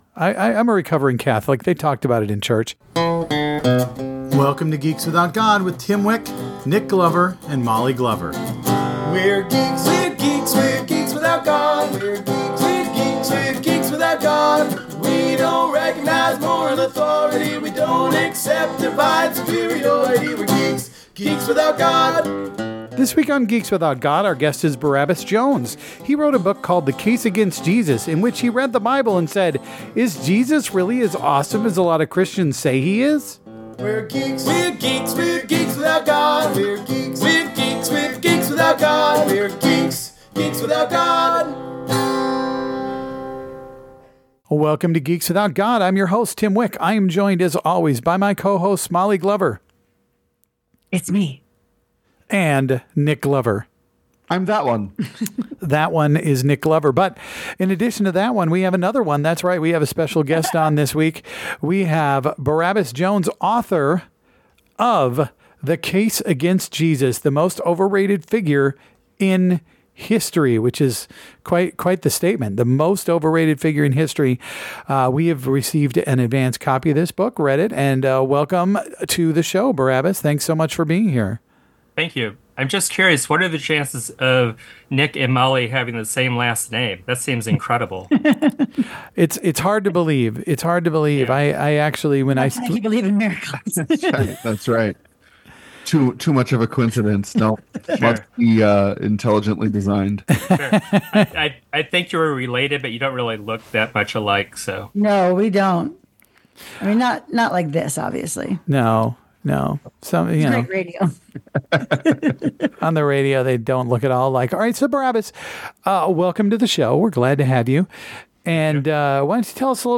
Geeks Without God is a podcast by three atheist/comedian/geeks. We'll talk about geeky stuff, atheist issues and make jokes.